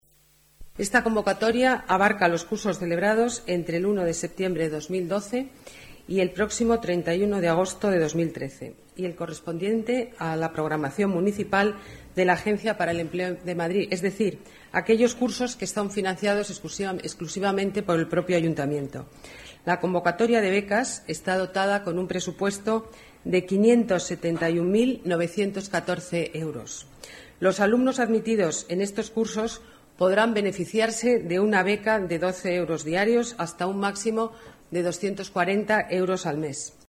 Nueva ventana:Declaraciones de la alcaldesa de Madrid, Ana Botella